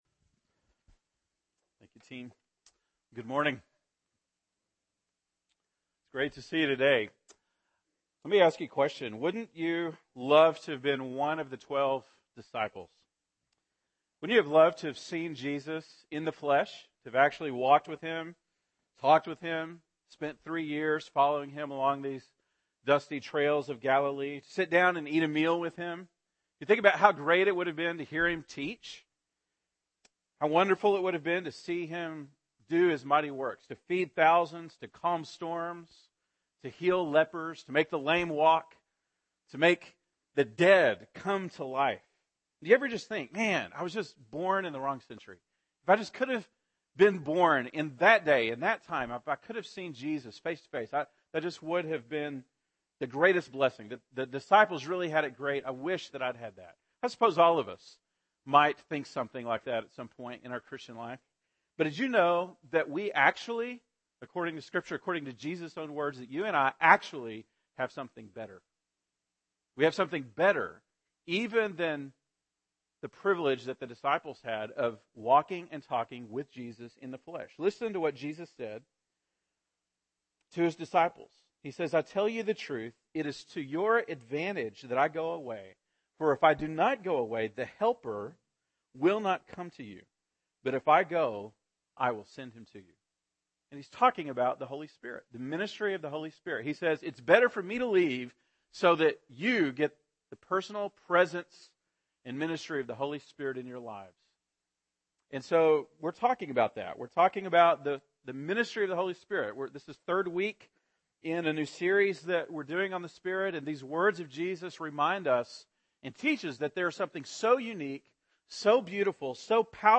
October 27, 2013 (Sunday Morning)